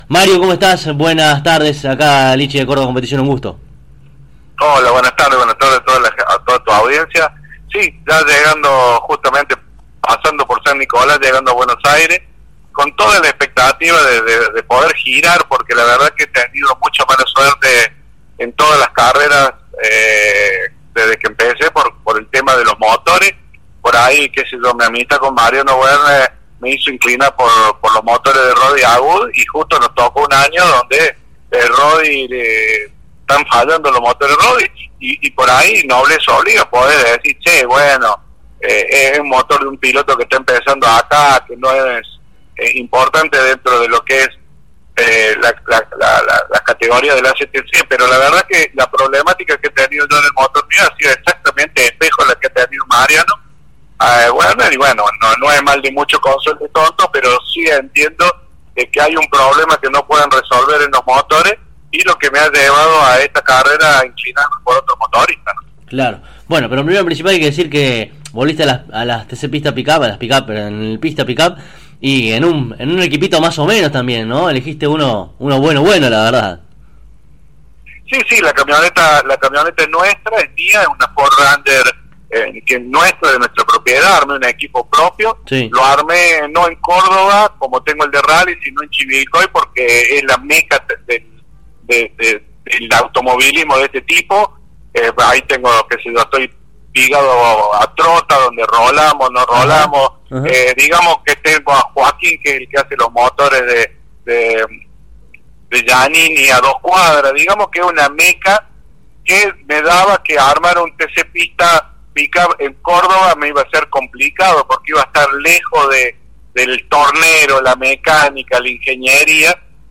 A continuación podrás escuchar esta jugosa entrevista de manera completa: